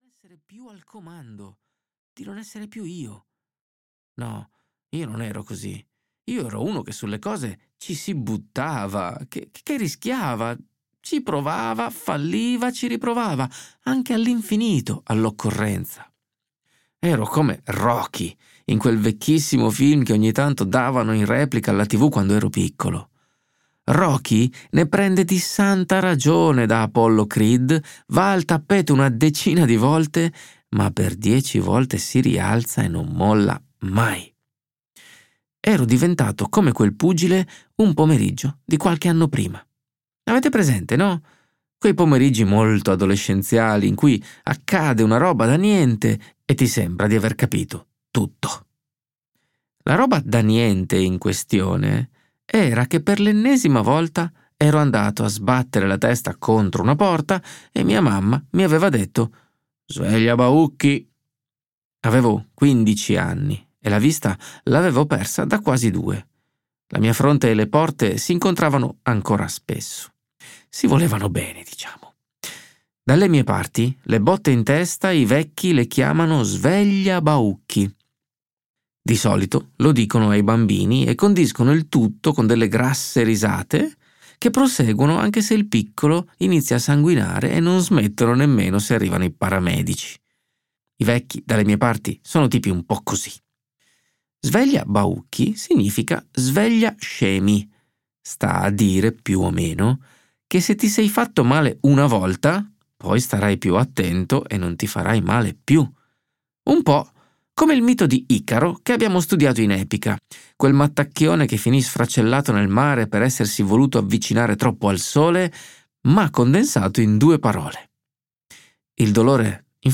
"Più forte di ogni addio" di Enrico Galiano - Audiolibro digitale - AUDIOLIBRI LIQUIDI - Il Libraio
• Letto da: Enrico Galiano